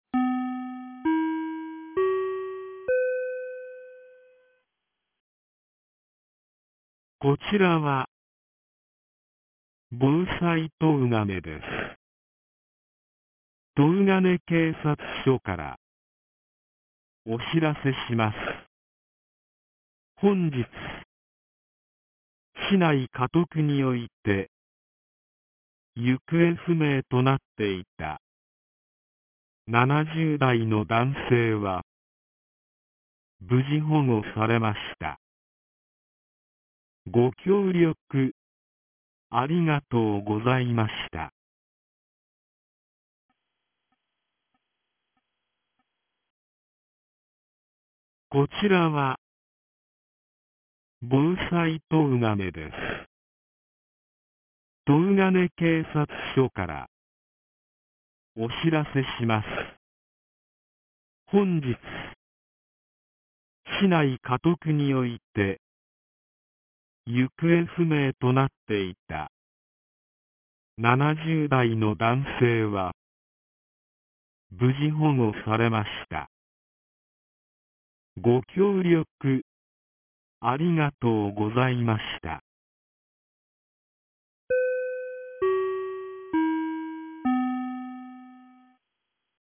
2025年10月23日 17時20分に、東金市より防災行政無線の放送を行いました。